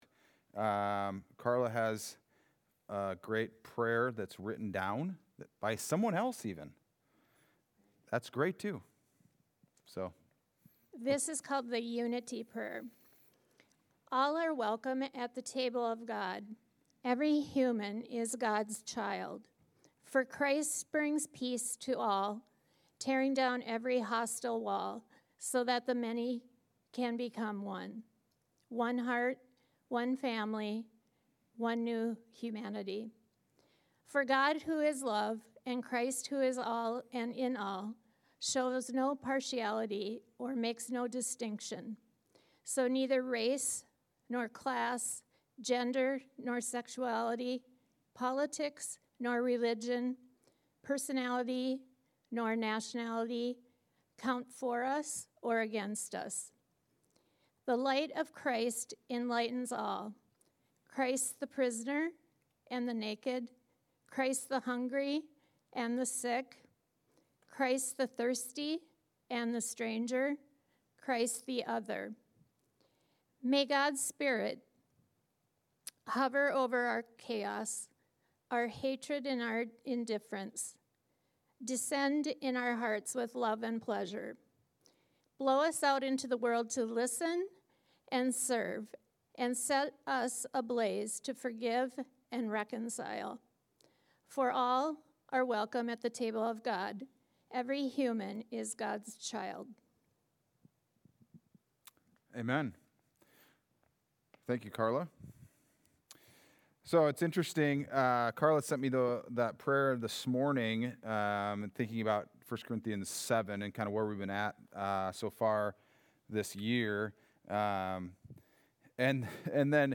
Wednesday Adult Study: 11-5-25